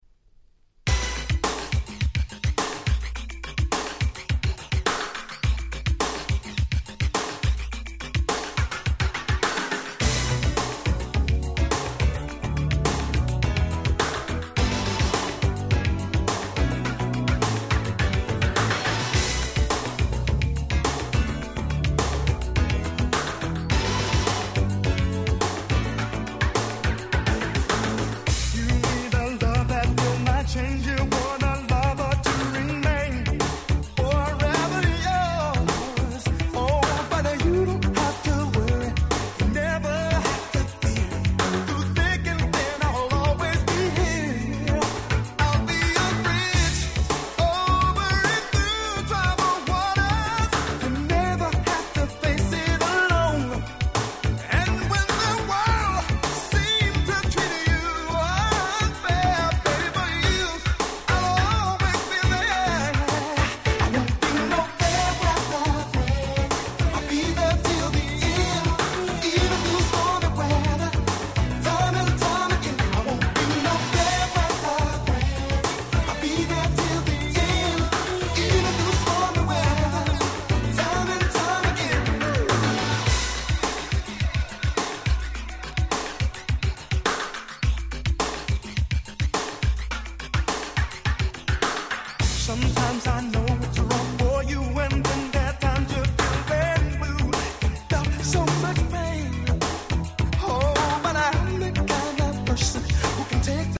90' Super Hit R&B LP !!